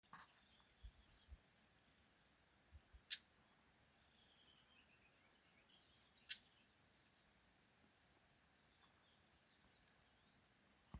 Klusais ķauķis, Iduna caligata
Administratīvā teritorijaLīvānu novads
Piezīmes/ticamāk izpr., atbild ļoti klusi.